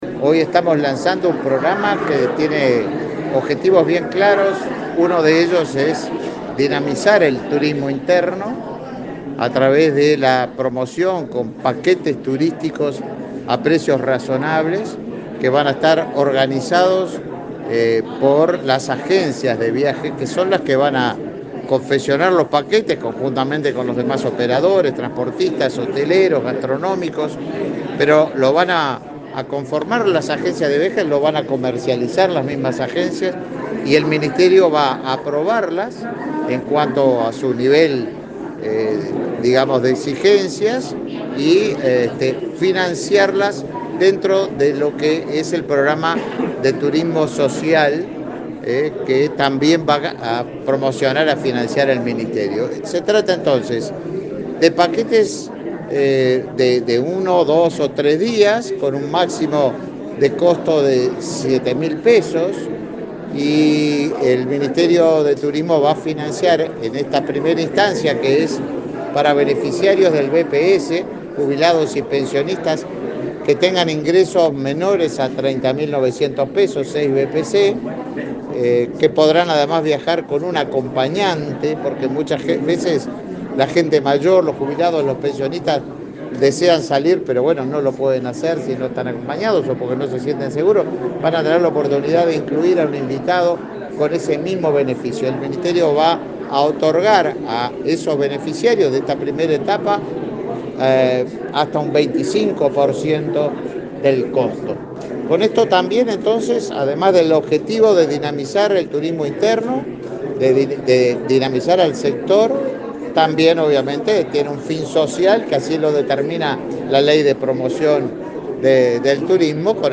Declaraciones a la prensa del ministro de Turismo, Tabaré Viera